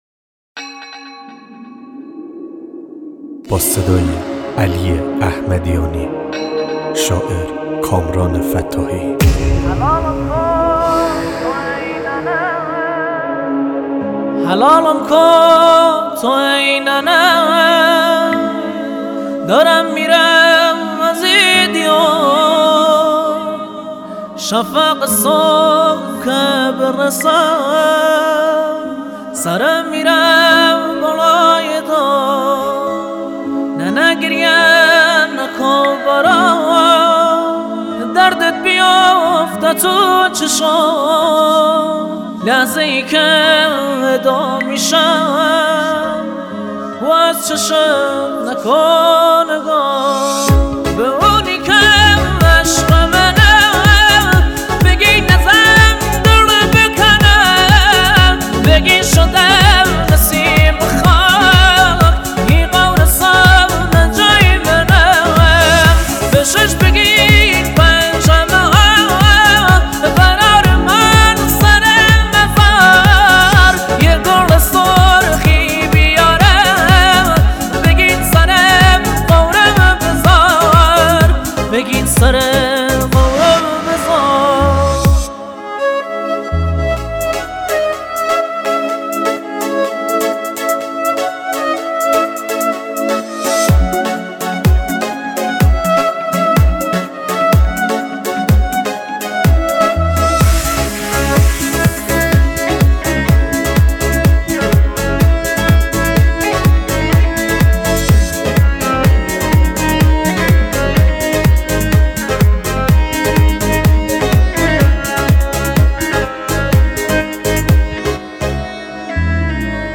آهنگ سوزناک و دلخراش